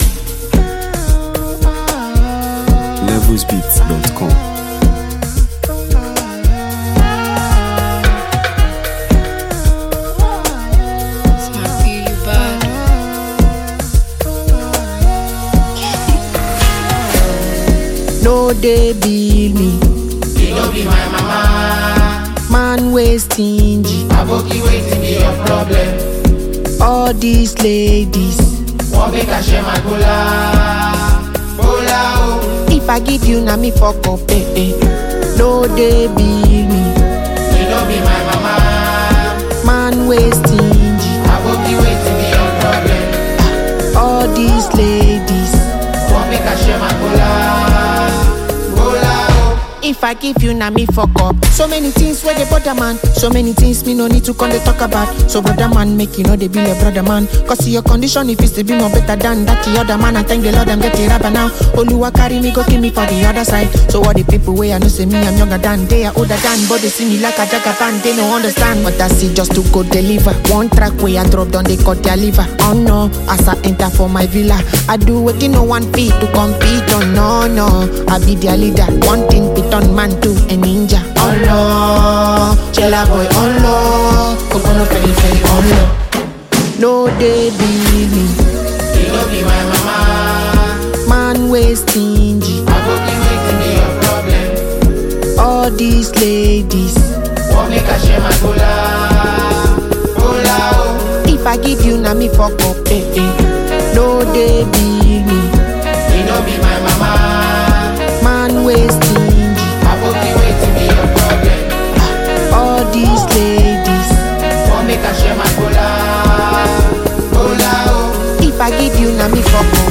the multi-talented Nigerian singer and lyricist